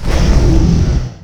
wav / general / combat / creatures / dragon / he / attack3.wav
attack3.wav